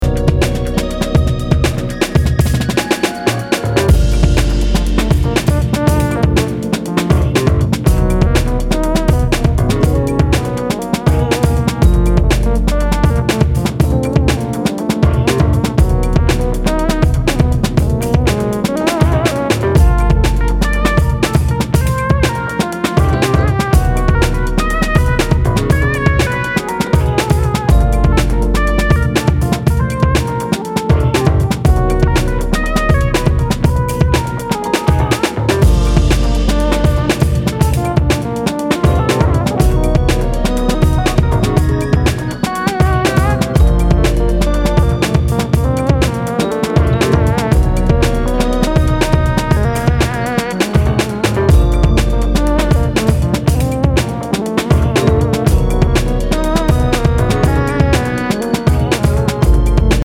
ブロークンビーツ的なドラミングにファンキーなベース・ライン
開放的な空気感を纏った爽やかで抜けのあるジャズファンク・ハウス〜ダウンテンポを繰り広げています。